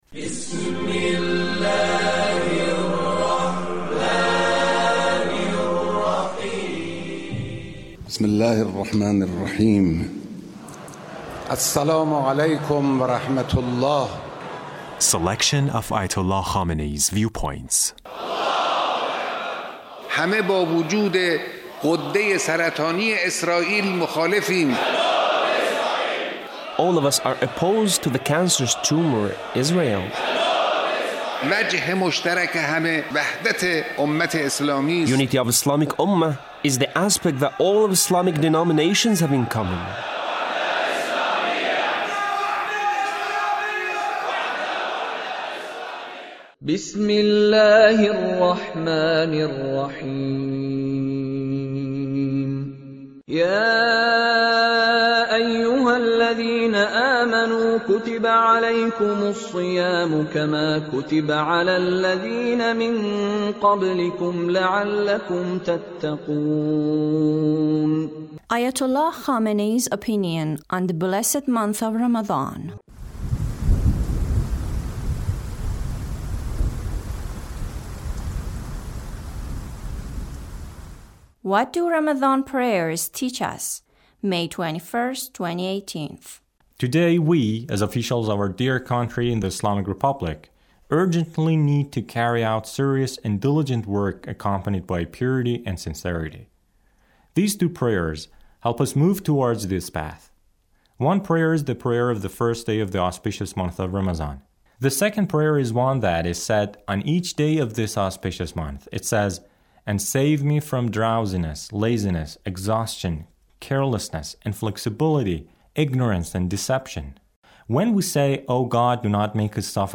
Leader's speech (89)